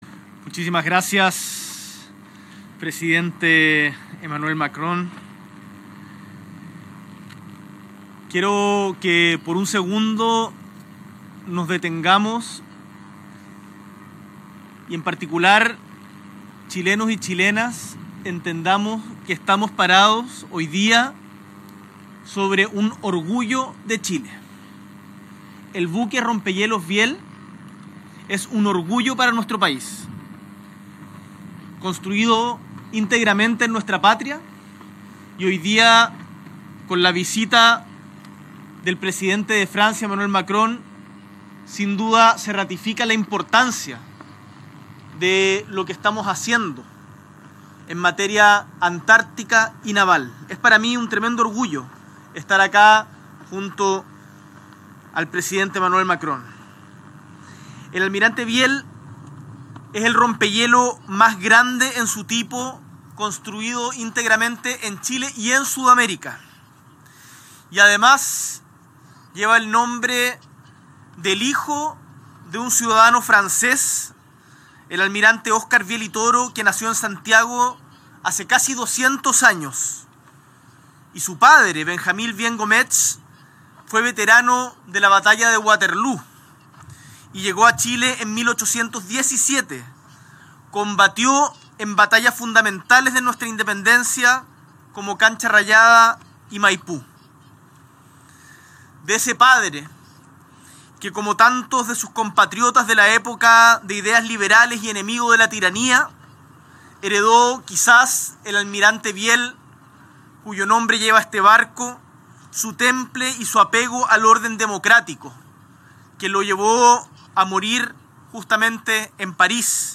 S.E. el Presidente de la República, Gabriel Boric Font, junto al Presidente de la República Francesa, Emmanuel Macron, visitan el buque rompehielos Almirante Viel de la Armada de Chile